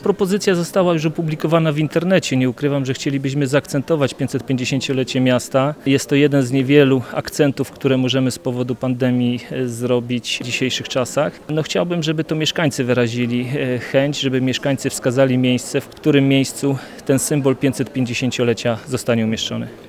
Mówi prezydent miasta Jacek Wiśniewski.